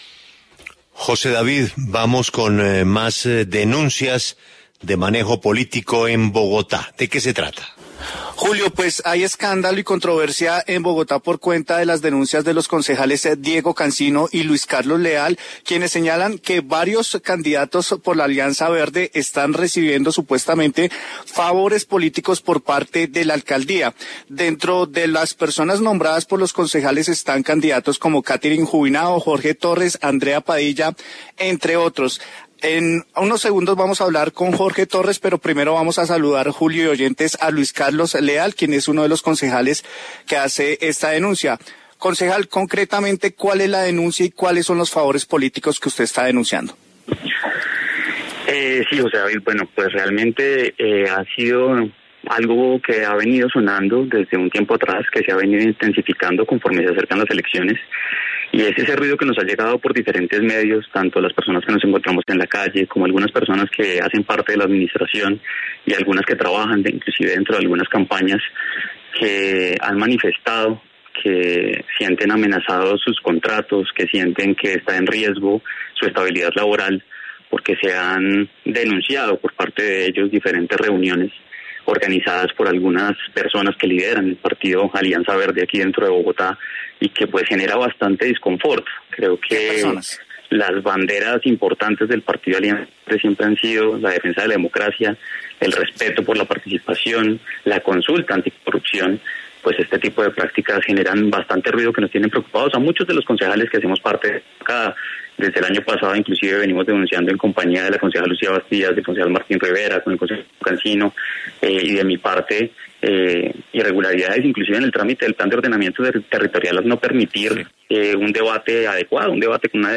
En diálogo con La W, el concejal Luis Carlos Leal aseguró que habría tráfico de votos al interior del Concejo de Bogotá. Responde el exconcejal Jorge Torres.